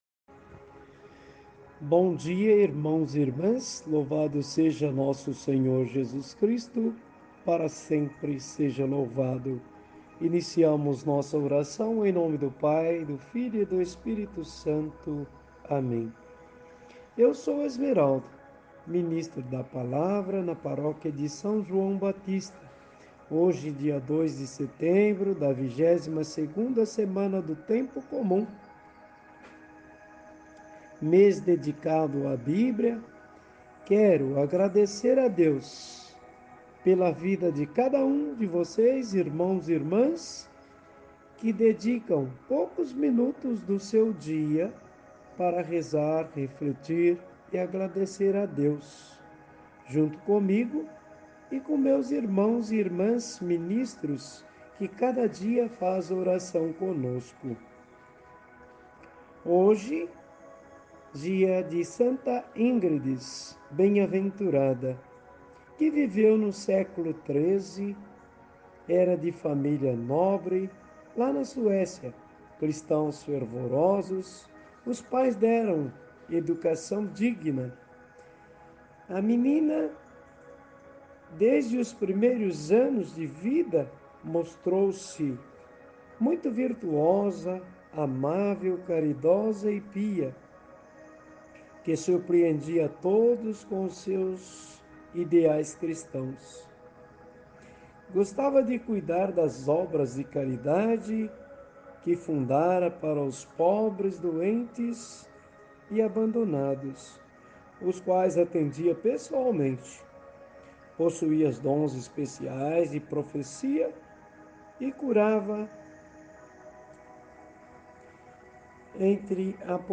Evangelho do Dia